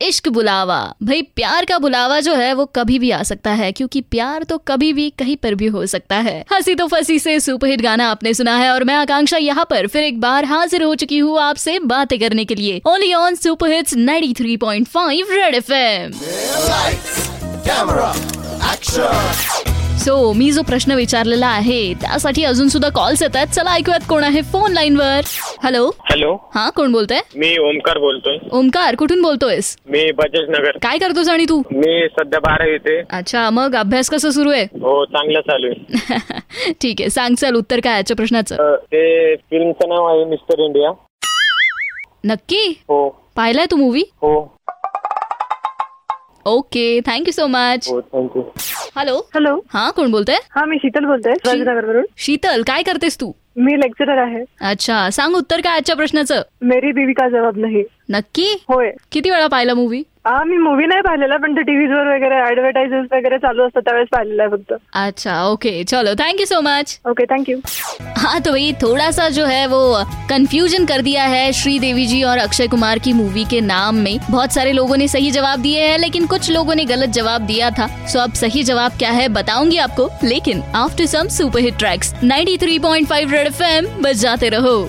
interacting with listners in Twist &Turn